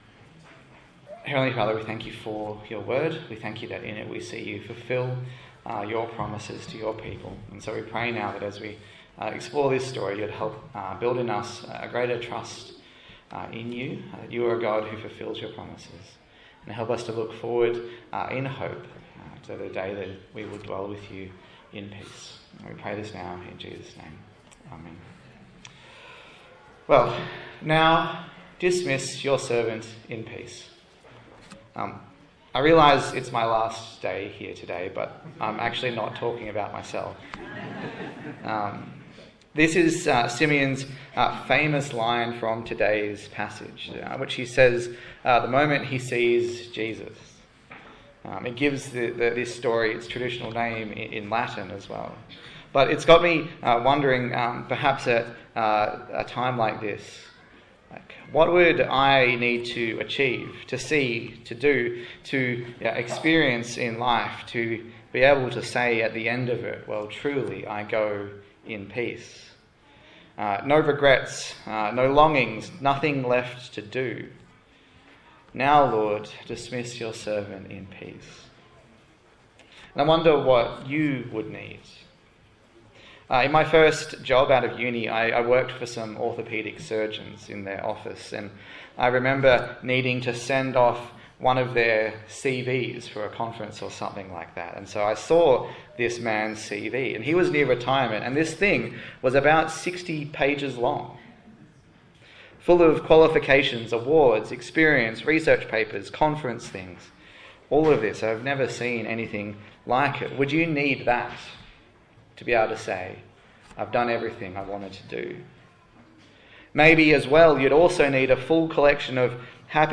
Passage: Luke 2:22-35 Service Type: Sunday Morning